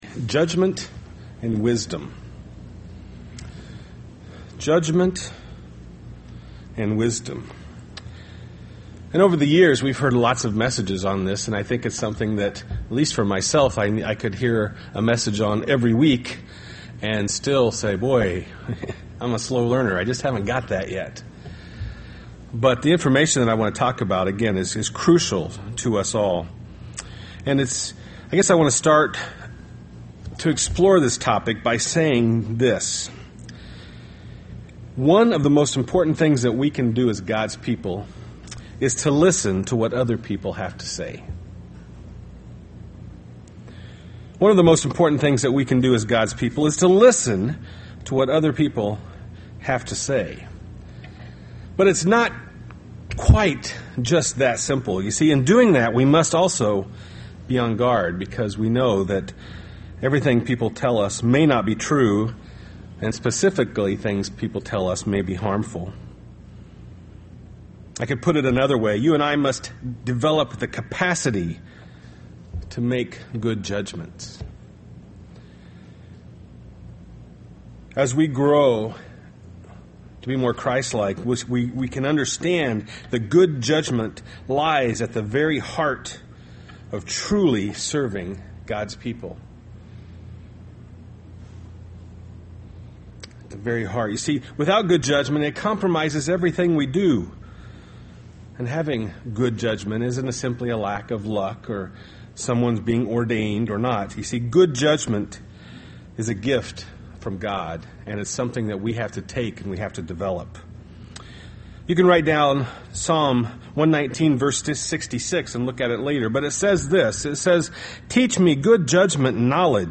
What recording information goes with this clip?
Given in Beloit, WI Milwaukee, WI